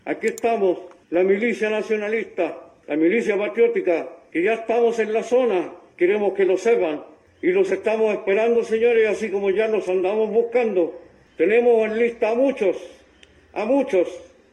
Un hombre, con una bufanda cubriendo parte de su rostro, usando gafas y un gorro con el símbolo de la agrupación Patria y Libertad que operó en Chile desde 1971, en contra del gobierno de Salvador Allende, aparece en un video vistiendo un chaleco táctico y ropa de mimetismo, informando que ya están en la zona para combatir a un grupo terrorista que se jacta de la propiedad de las tierras.